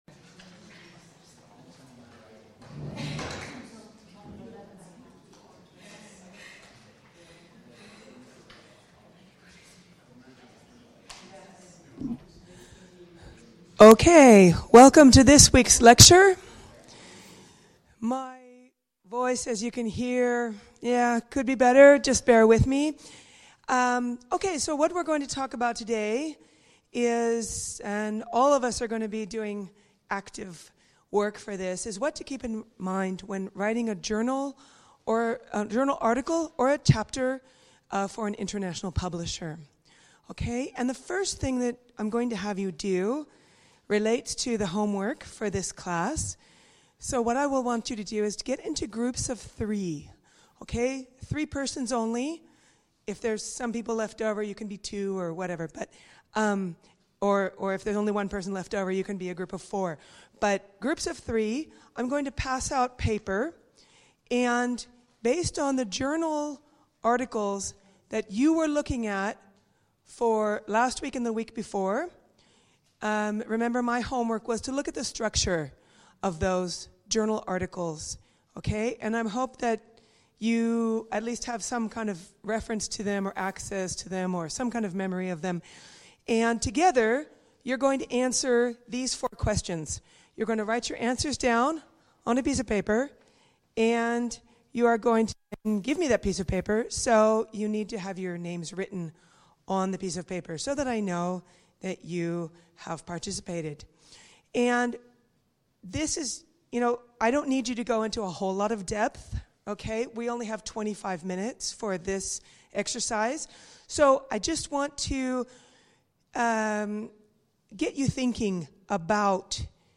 Lecture 4